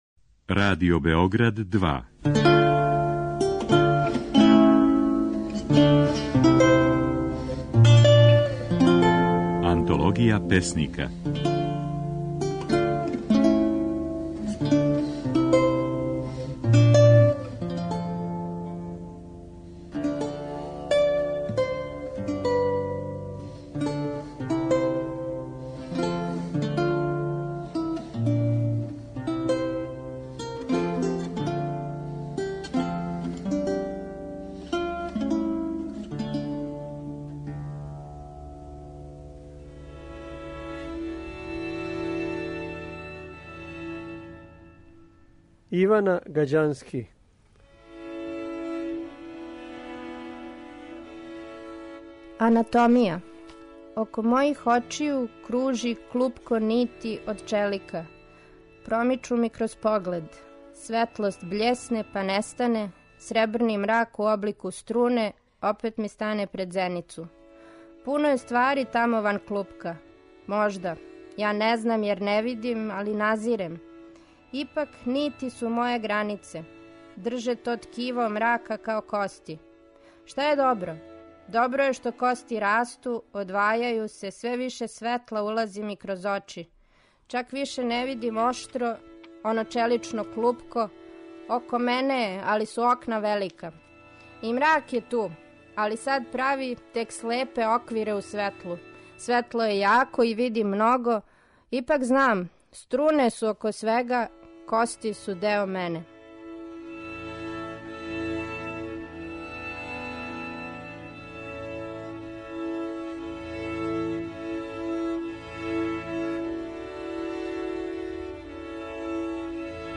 можете чути како своје стихове говори